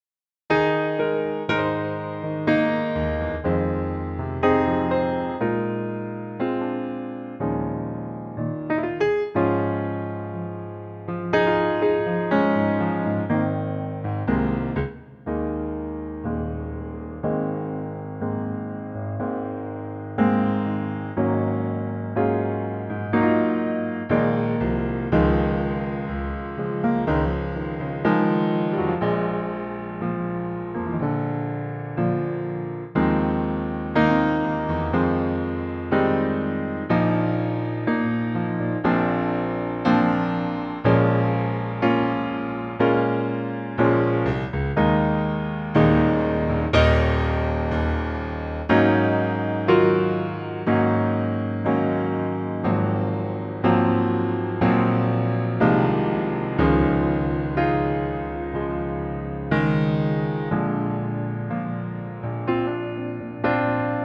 key - Ab - vocal range - C to Eb (optional F)